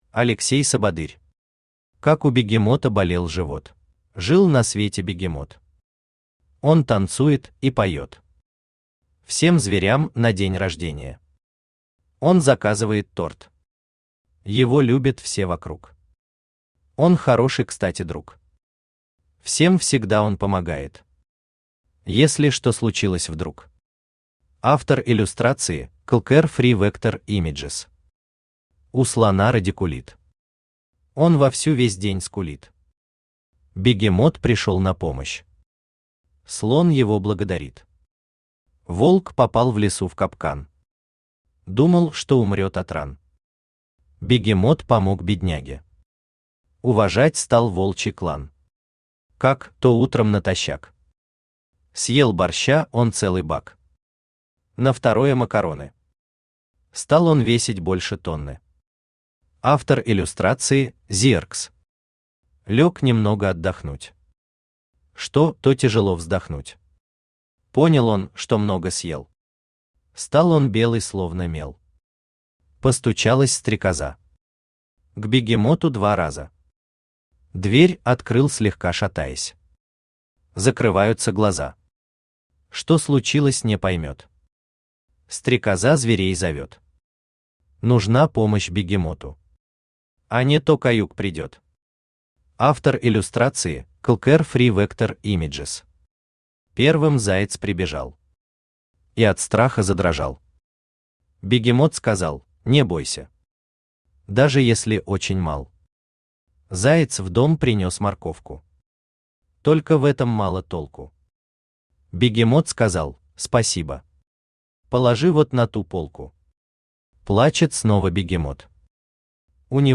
Аудиокнига Как у бегемота болел живот | Библиотека аудиокниг
Aудиокнига Как у бегемота болел живот Автор Алексей Сабадырь Читает аудиокнигу Авточтец ЛитРес.